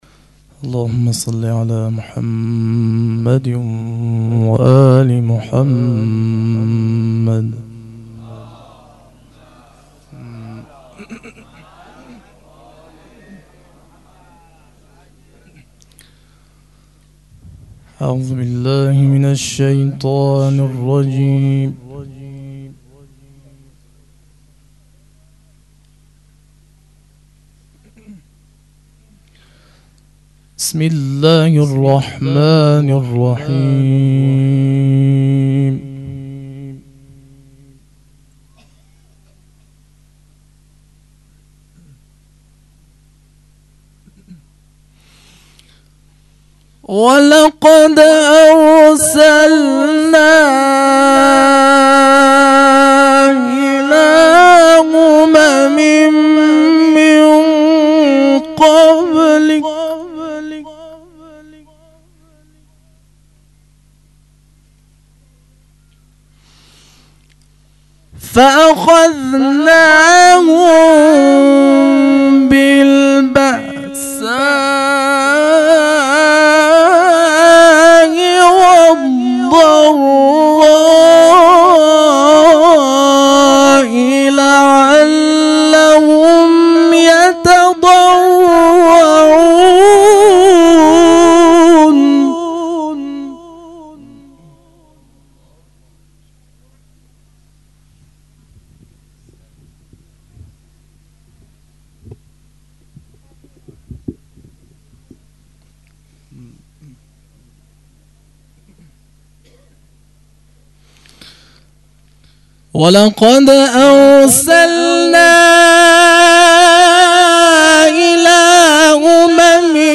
هیئت مکتب الزهرا(س)دارالعباده یزد
0 0 تلاوت مجلسی توسط
فاطمیه دوم_شب پنجم